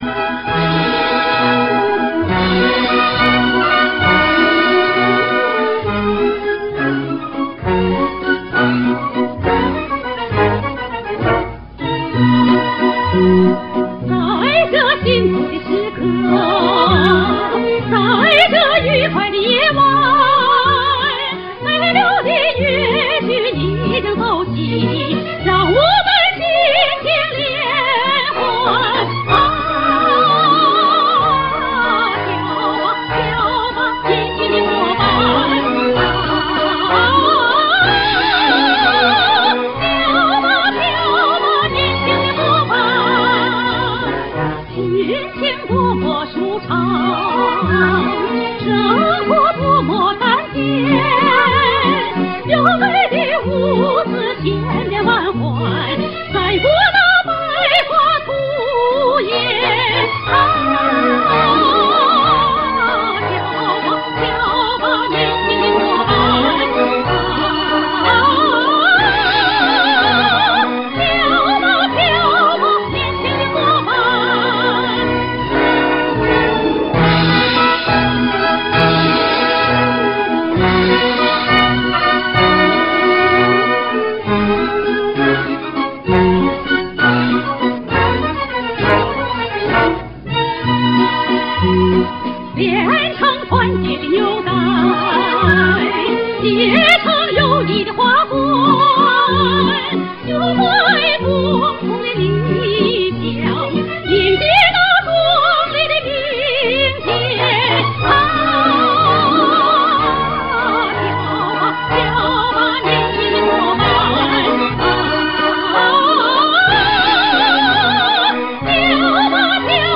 首唱录音
这是一首女高音独唱歌曲